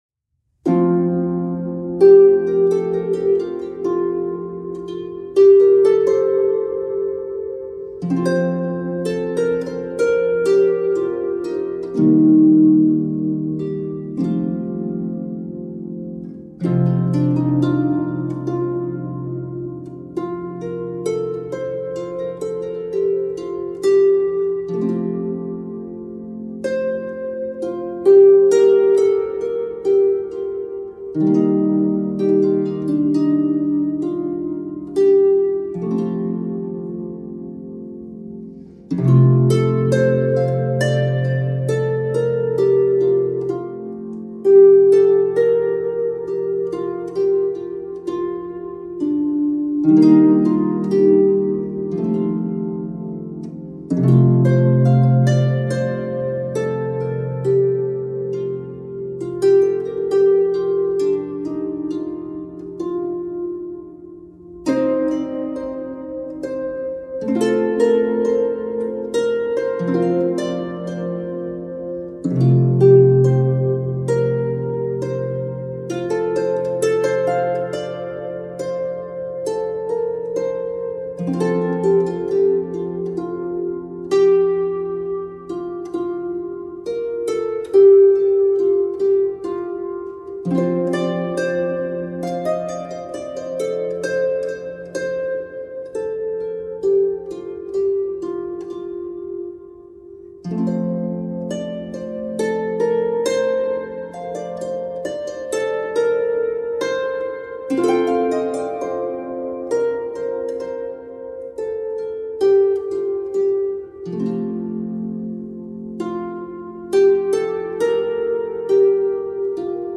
Many songs are modal.